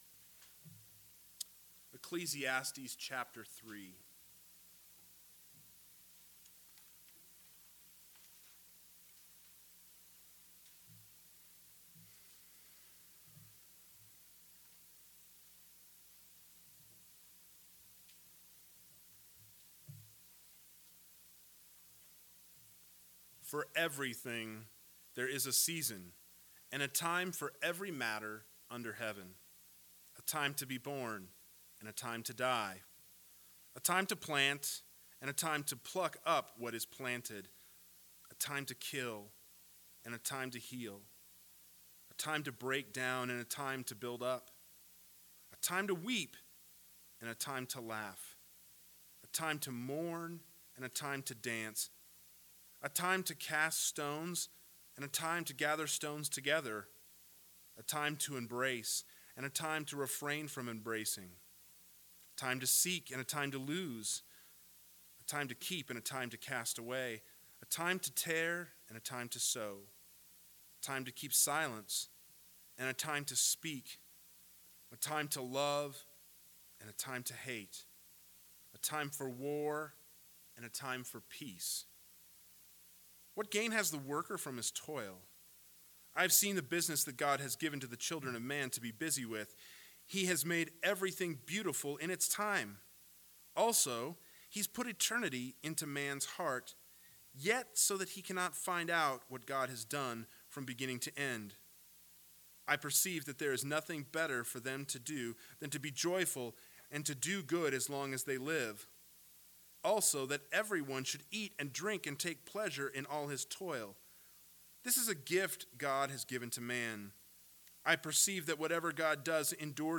PM Sermon